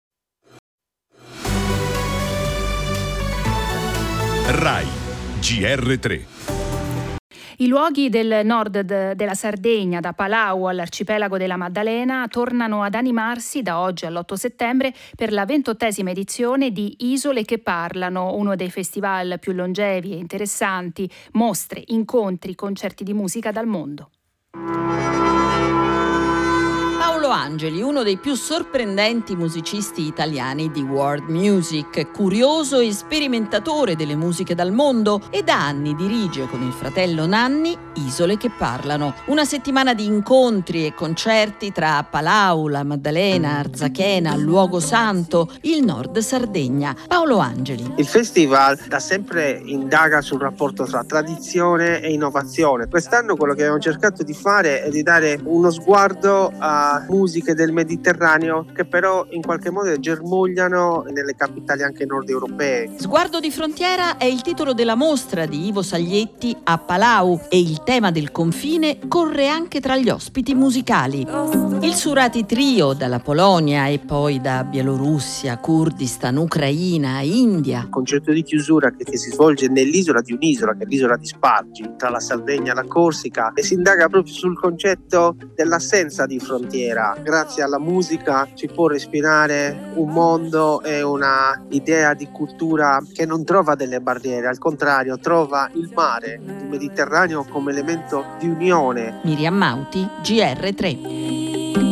Servizio di RAI GR1 delle ore 10:00 e replica su RAI GR3 delle ore 18:45